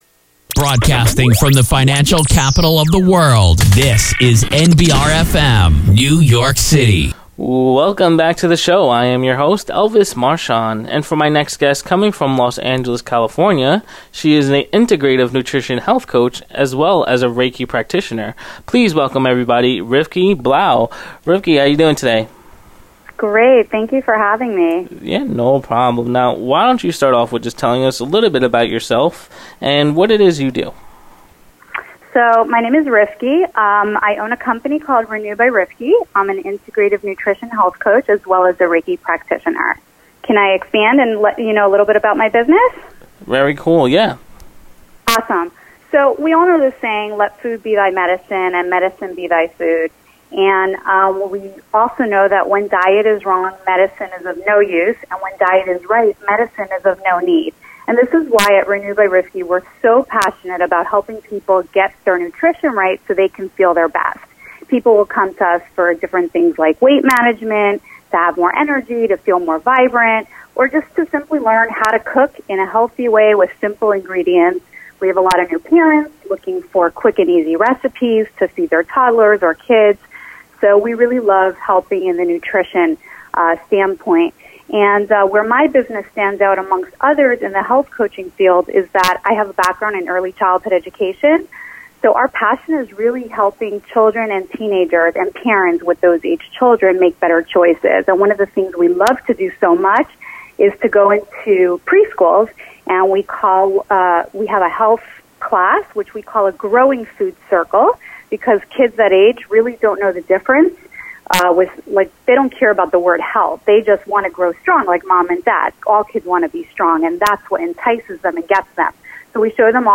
Podcast Guest
nbr-radio-interviews-trim.mp3